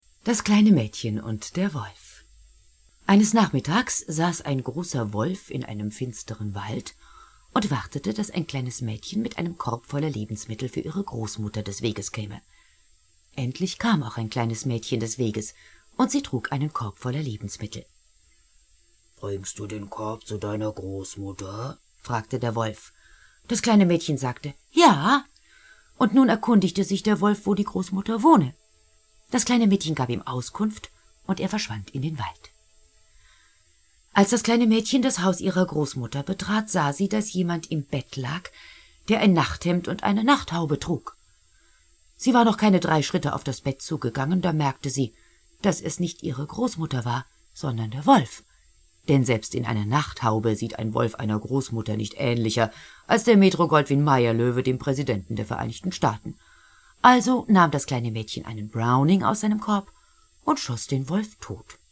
Schauspielern, Moderatorin, Sprecherin. Synchronsprecherin. Hörbuch-Sprecherin.
Sprechprobe: Industrie (Muttersprache):